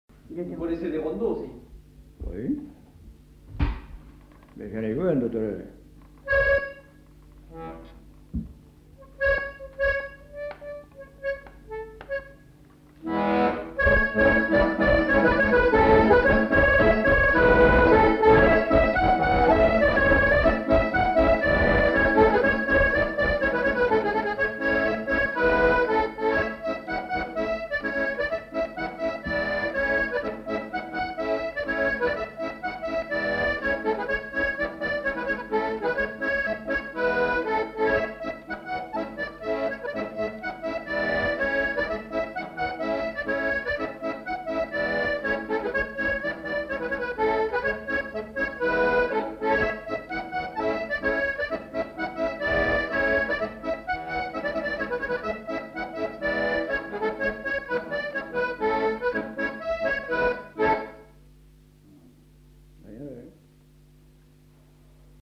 Aire culturelle : Savès
Lieu : Beaumont-de-Lomagne
Genre : morceau instrumental
Instrument de musique : accordéon diatonique
Danse : rondeau